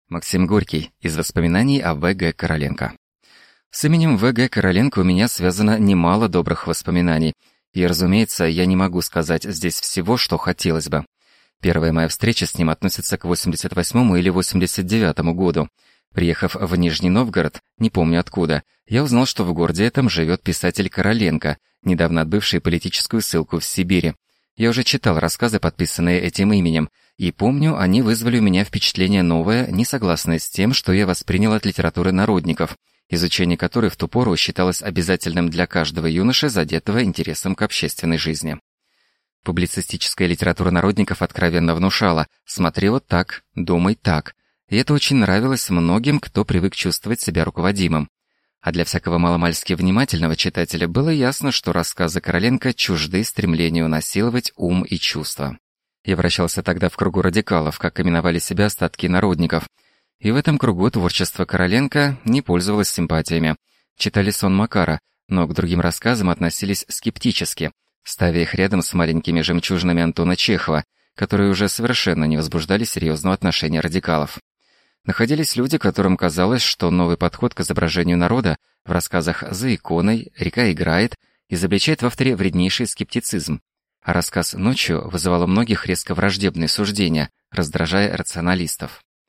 Аудиокнига Из воспоминаний о В. Г. Короленко | Библиотека аудиокниг